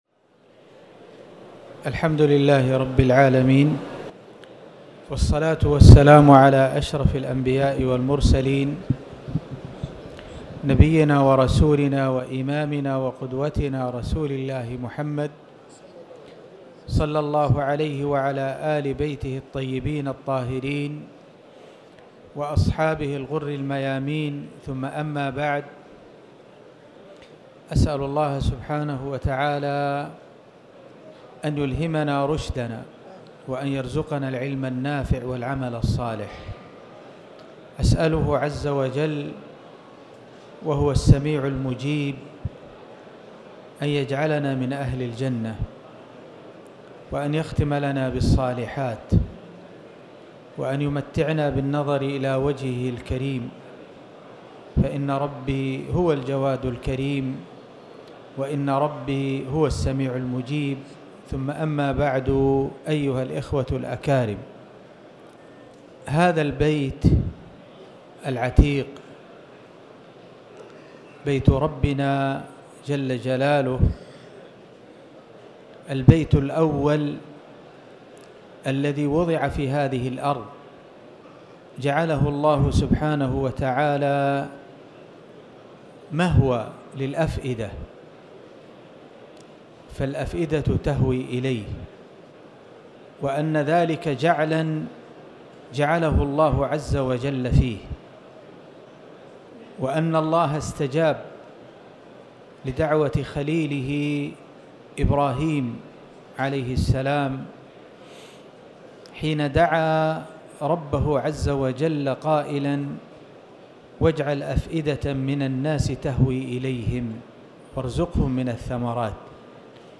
المكان: المسجد الحرام
6ذو-الحجة-محاضرة-بناء-المفاهيم-الصحيحة-في-ضوء-شعيرة-الحج111.mp3